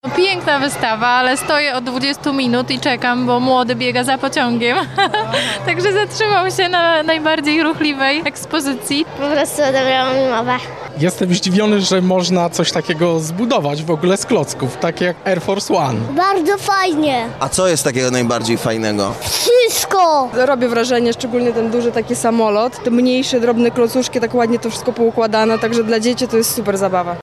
mowia_lublinianie-12.mp3